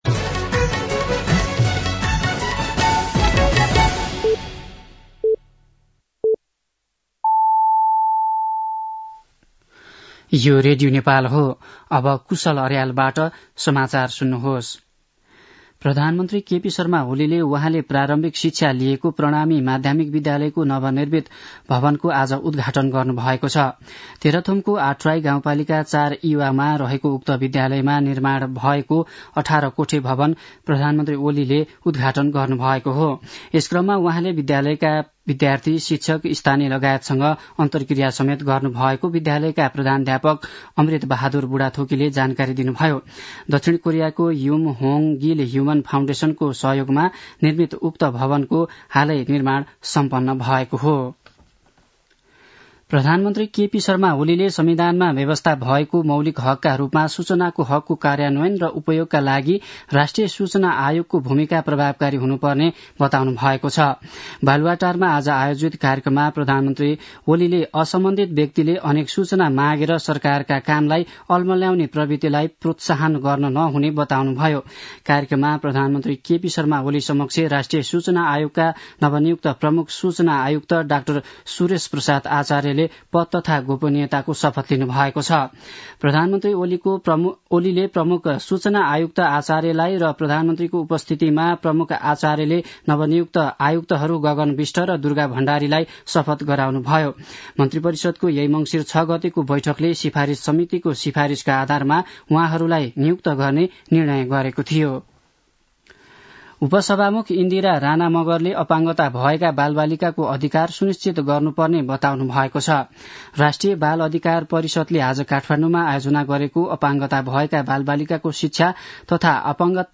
दिउँसो ४ बजेको नेपाली समाचार : २७ मंसिर , २०८१
4-pm-nepali-news-1-4.mp3